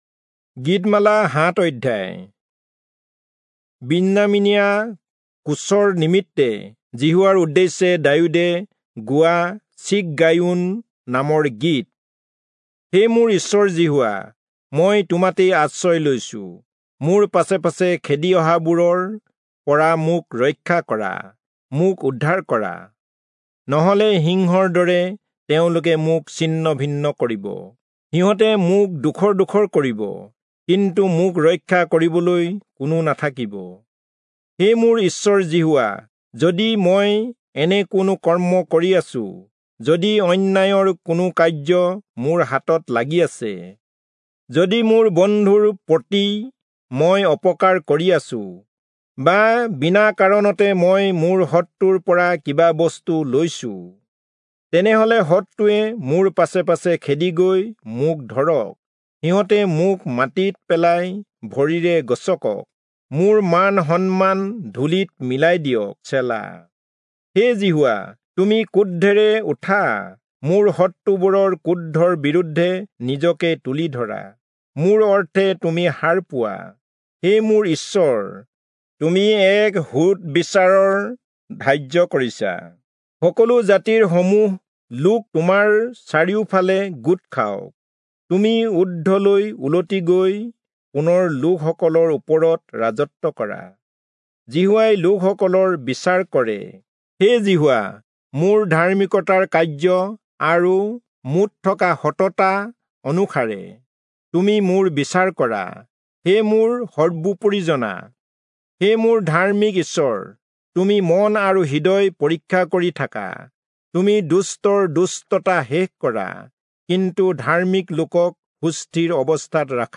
Assamese Audio Bible - Psalms 74 in Pav bible version